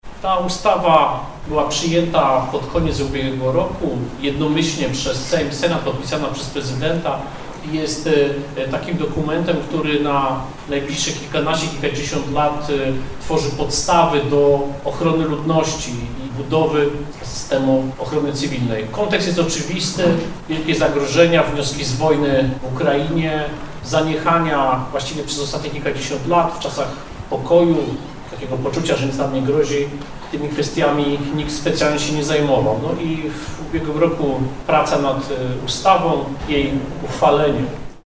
Kontekst jest oczywisty – mówi minister Tomasz Siemoniak, szef resortu MSWiA.